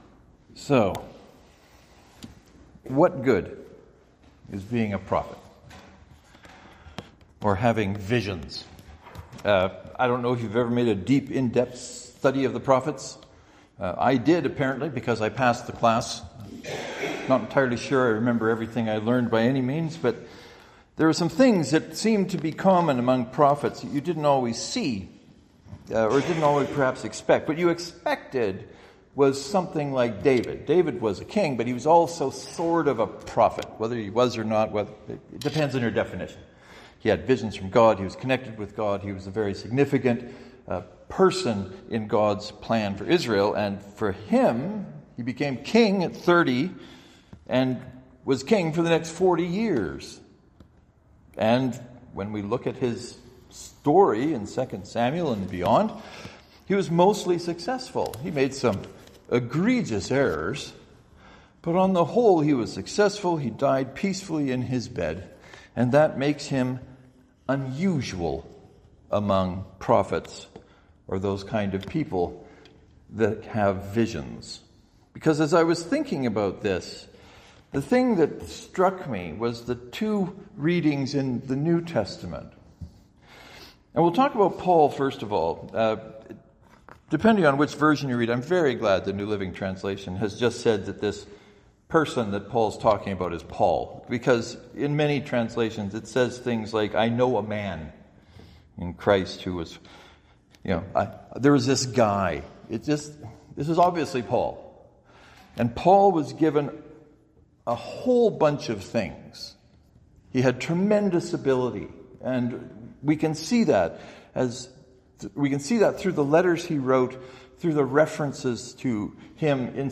I believe this sermon sees a first for me.